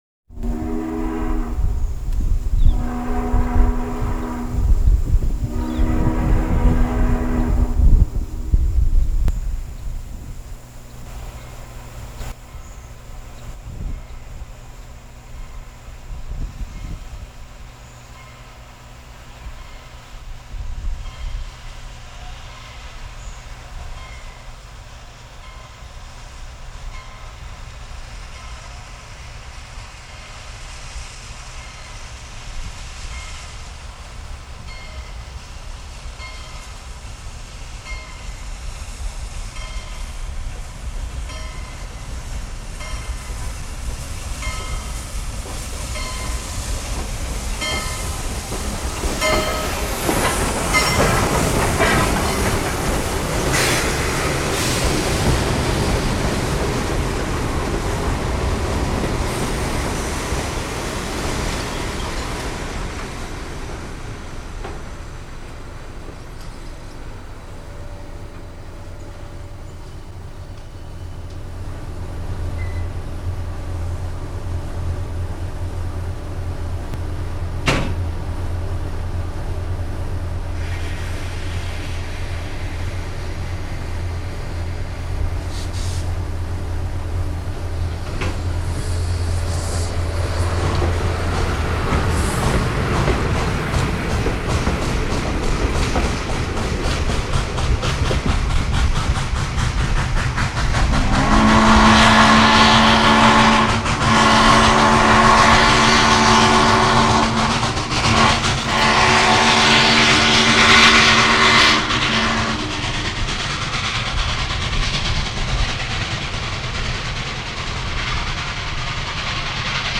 The J class used 3 chime Hancock "Steamboat" whistle (also used on K and E class passenger locos), essentially the same as used on UP 4-8-4, 4-6-6-4, and 4-8-8-4 locos.